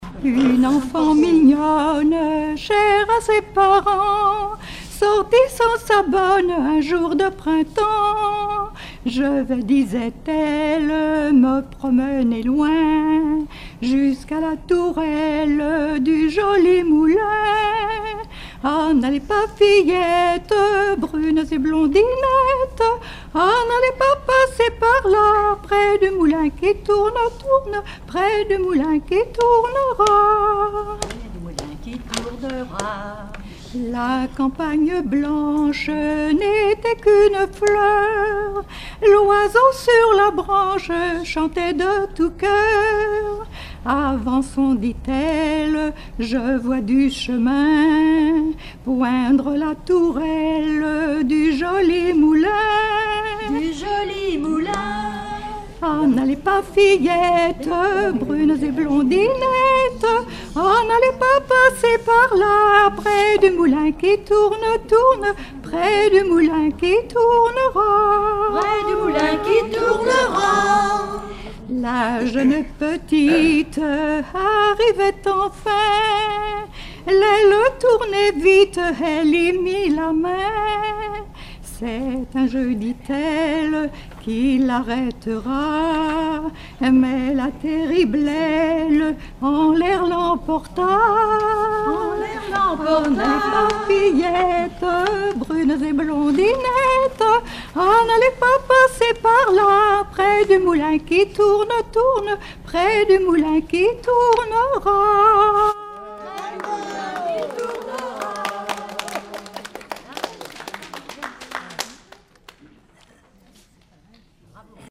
Genre strophique
Regroupement de chanteurs du canton
Pièce musicale inédite